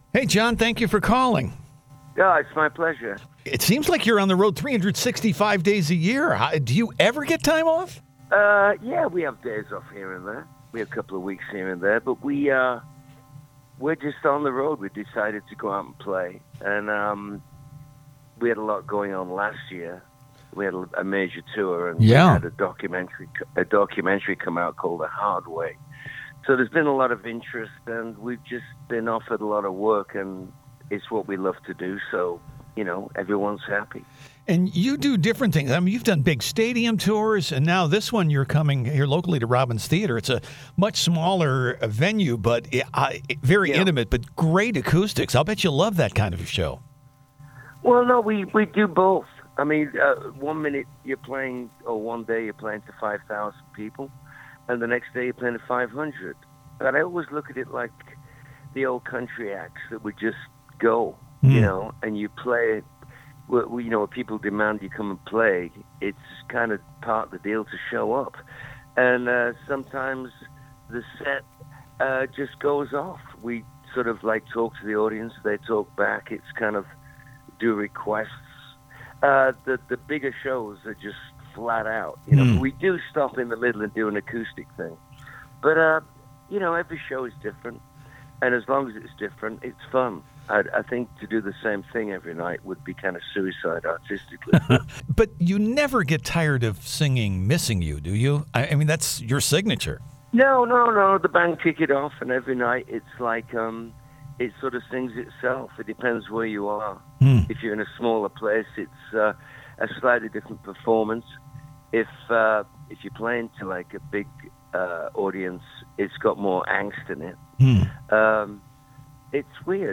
Full Interview https